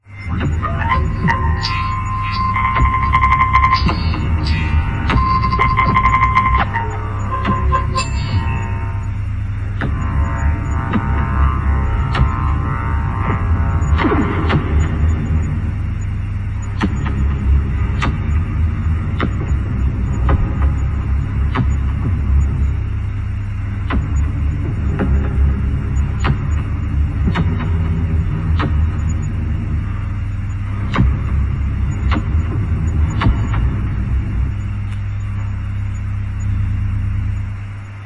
Flute
Synth modular